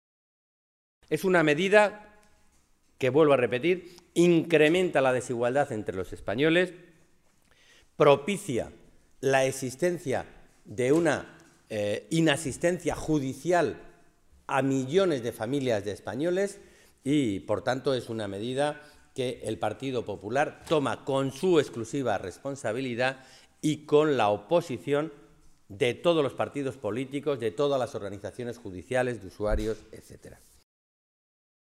Alejandro Alonso, diputado nacional del PSOE de Castilla-La Mancha
Cortes de audio de la rueda de prensa